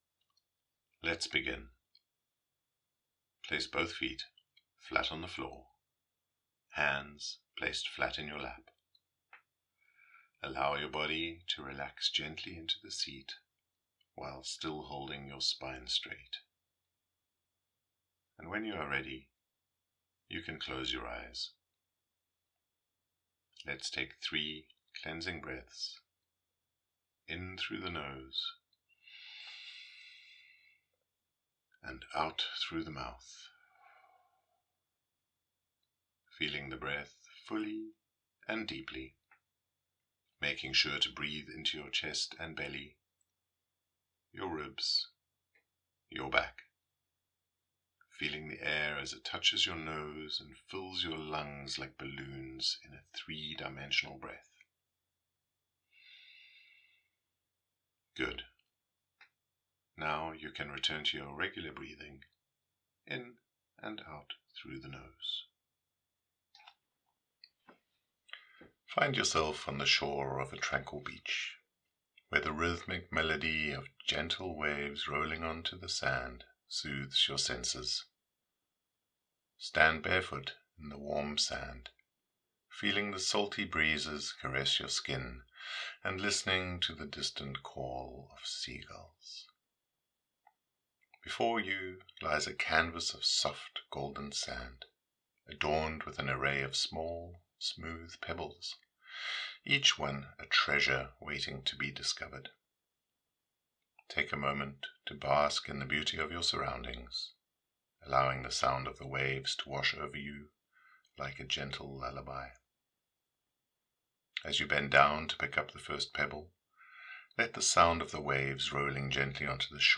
Pebbledash Meditation
WS20-meditation.mp3